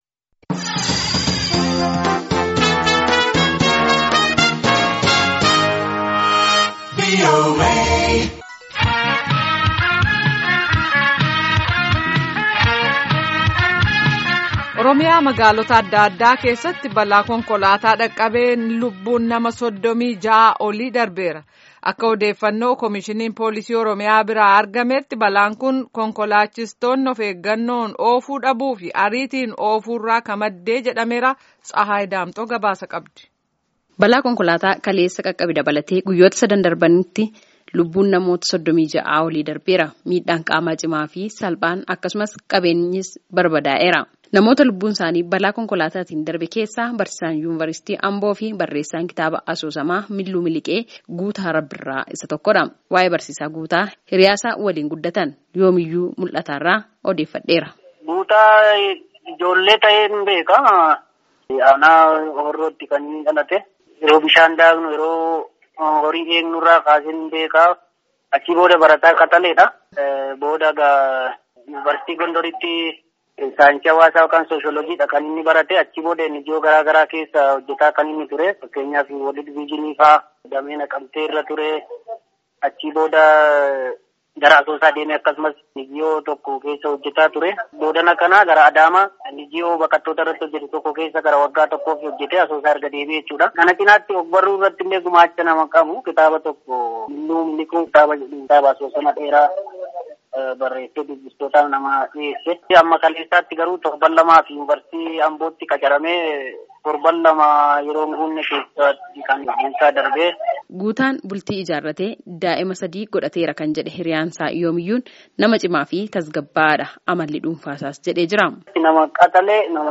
Gabaasaa guutuu caqasaa.